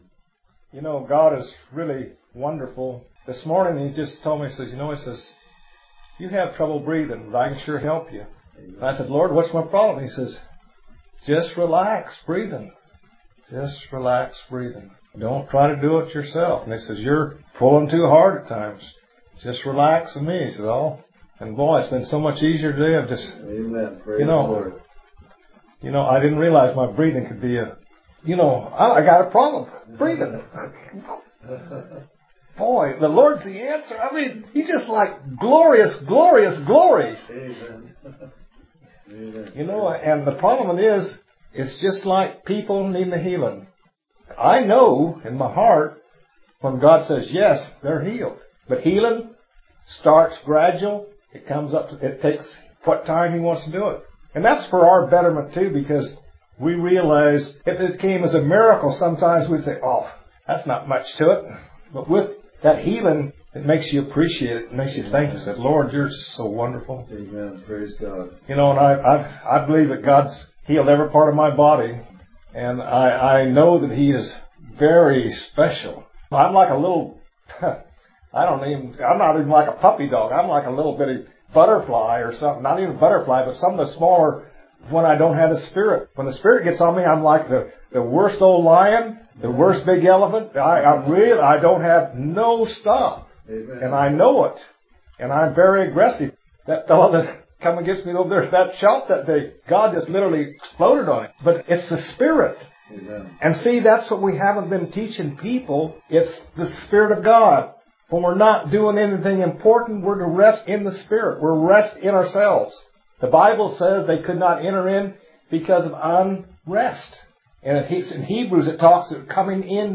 Ever Learning But Never Able – This 53 min. message is fantastic, with 2 prophecies and powerful prayer for your deliverance and healing!